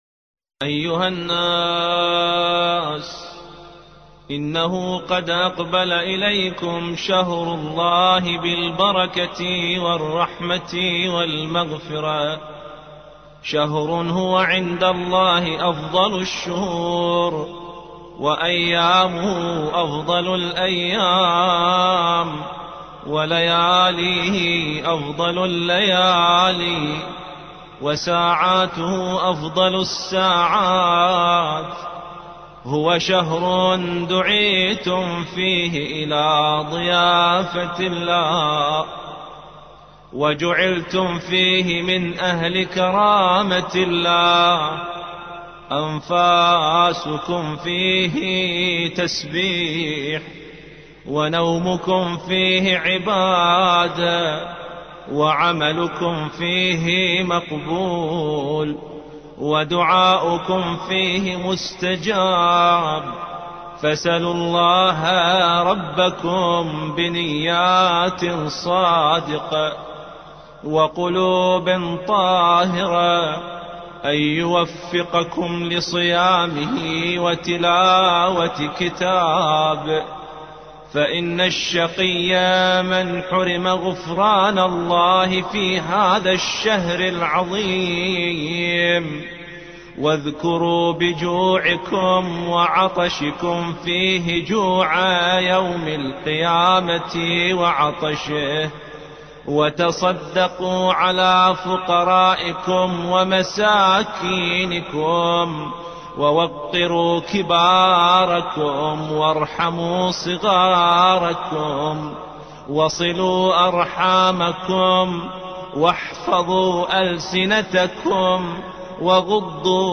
ملف صوتی آخر جمعة من شهر شعبان مستقبل شهر رمضان خطبة النبي الأكرم صل الله عليه وآله بصوت الشيخ الدكتور أحمد الوائلي